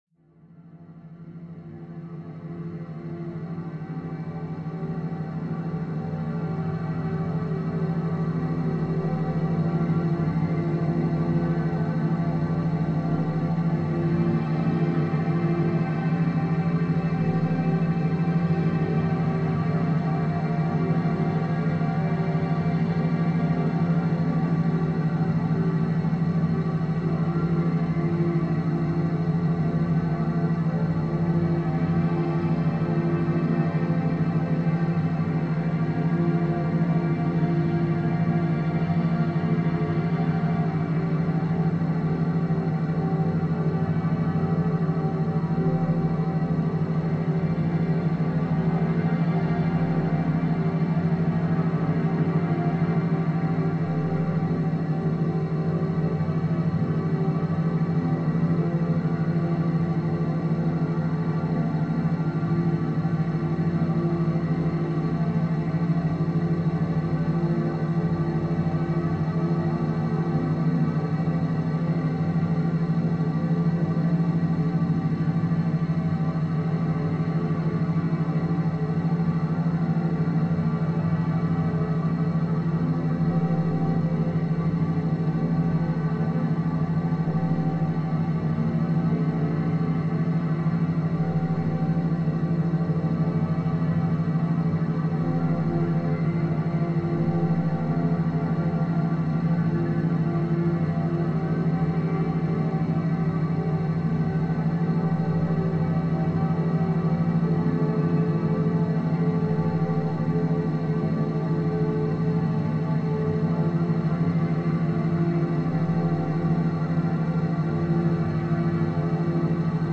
Enhanced Mental Focus – Binaural Audio for Concentration